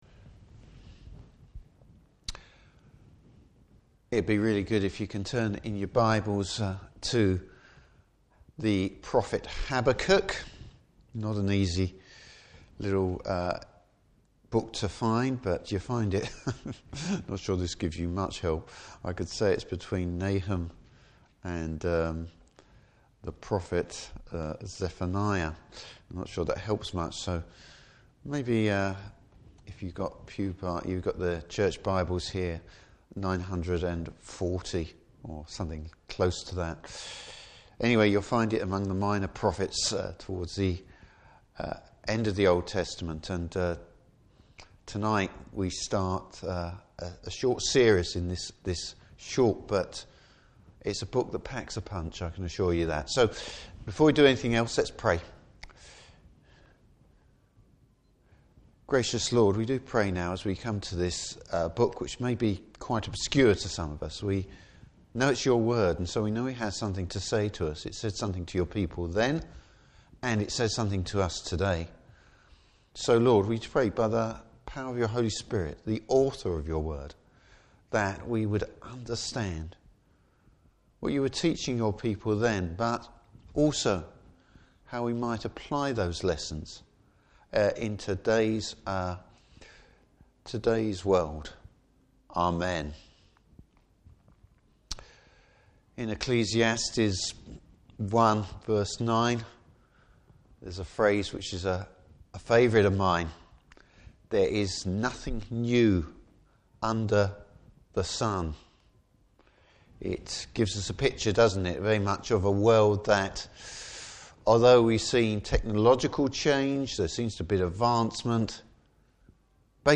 Service Type: Evening Service Habakkuk’s prayer of faith.